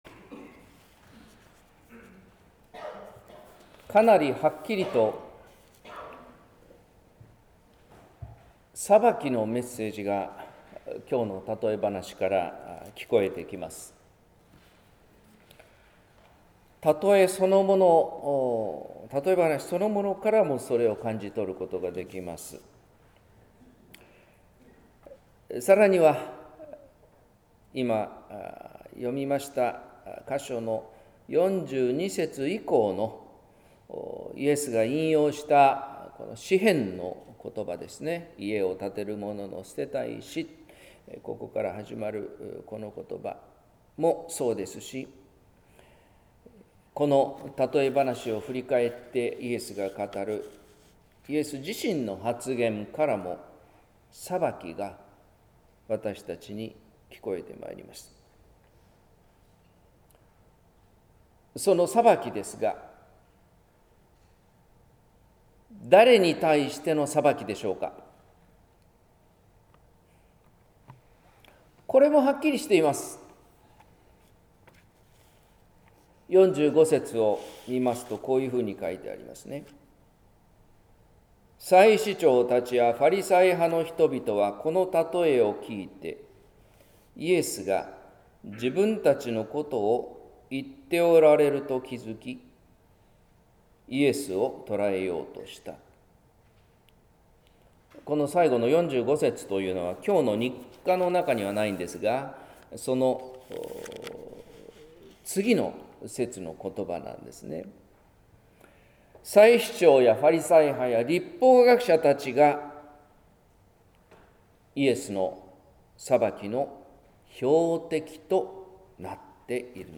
説教「不思議に見える石」（音声版） | 日本福音ルーテル市ヶ谷教会